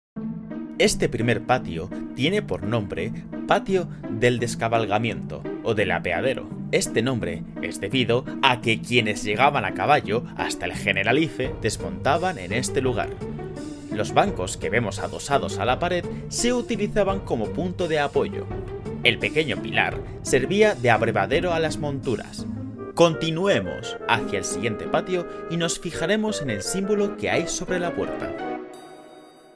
Demo audioguía
Castellano neutro
Young Adult